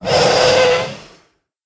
c_wrpstlkr_hit2.wav